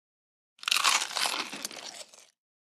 DINING - KITCHENS & EATING CHICKEN: INT: Crispy single destructive bite, reverb.